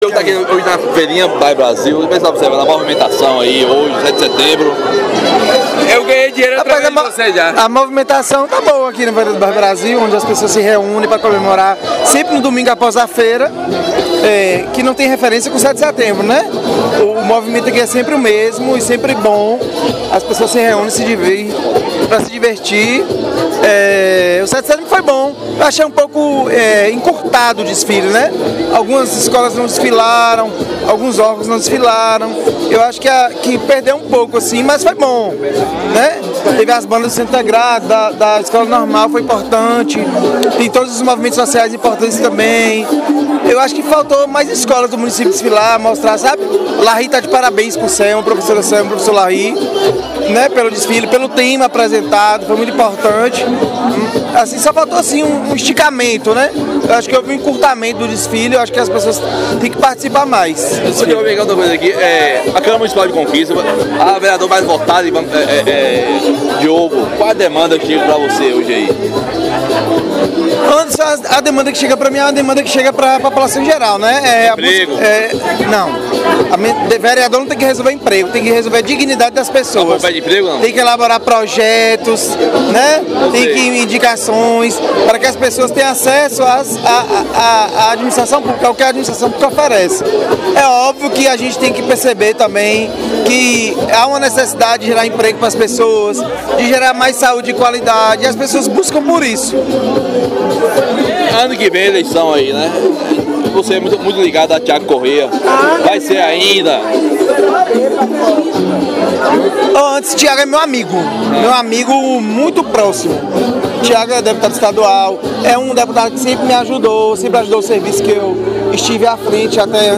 Na manhã desta terça-feira (15), o secretário participou do programa Sudoeste Agora , da Rádio Clube de Conquista, onde detalhou a proposta. Ele explicou que a ideia surgiu a partir da situação do campo com grama sintética da Lagoa das Bateias, que precisou ser interditado por uso excessivo, projetado para suportar 60 horas mensais, o campo vinha sendo utilizado por cerca de 600 horas.